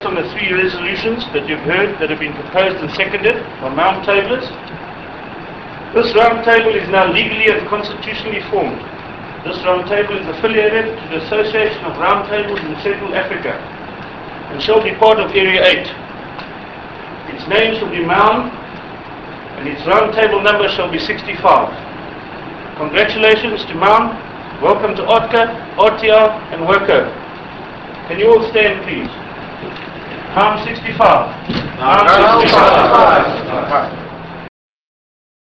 Maun 65 Charter